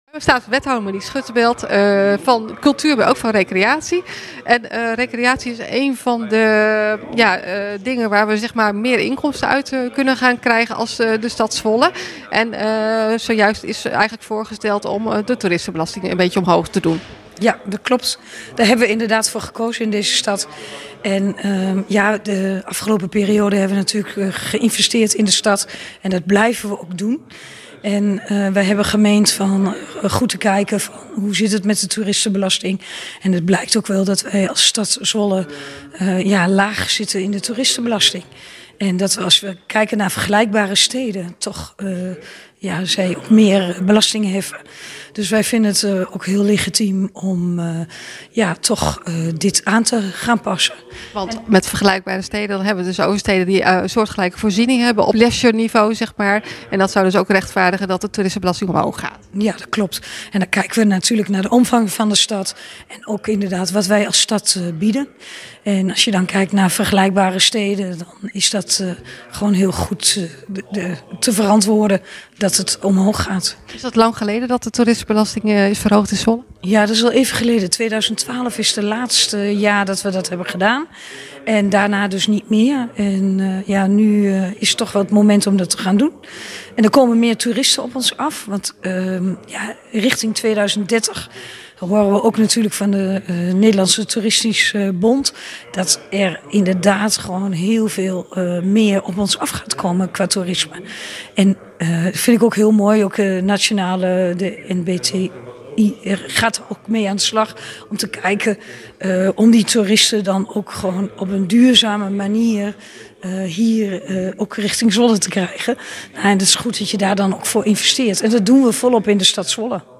Audio: Interview: Wethouder Monique Schuttenbeld van recreatie en toerisme legt uit waarom de toeristenbelasting wordt verhoogd